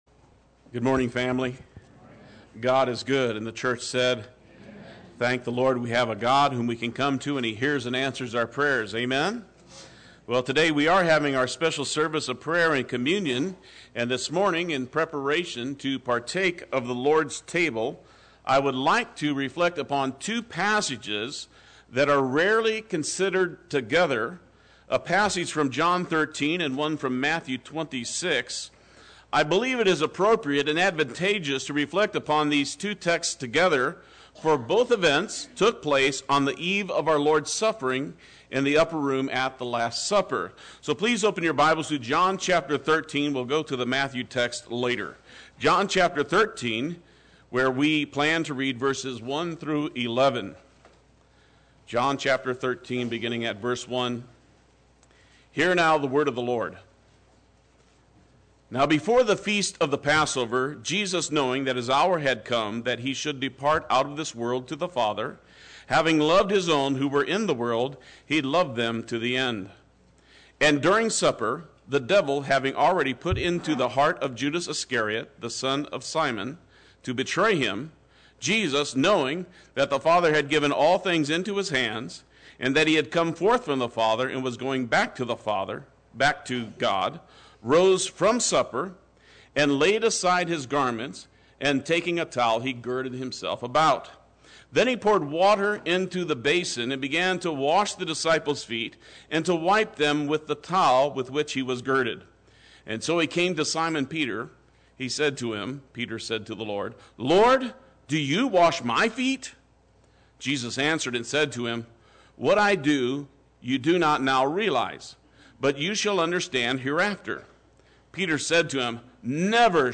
Play Sermon Get HCF Teaching Automatically.
“Prayer and Communion” Sunday Worship